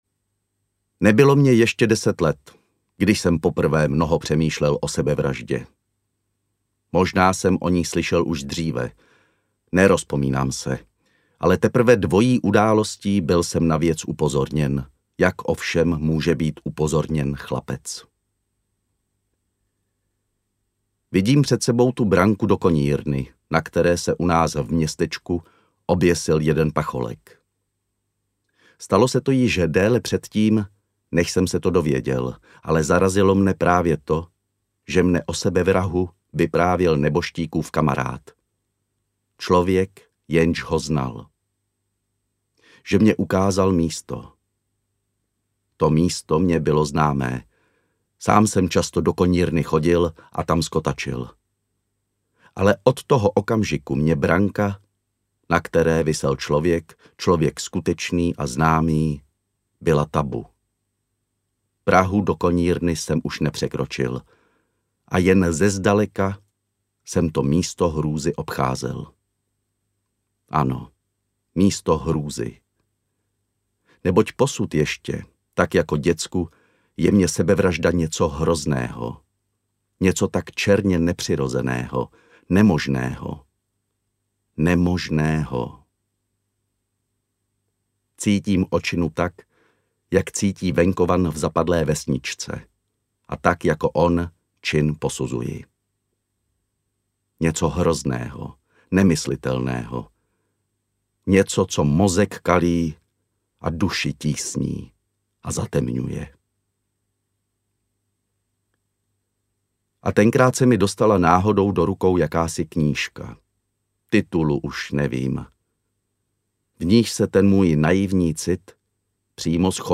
Moderní člověk a náboženství audiokniha
Ukázka z knihy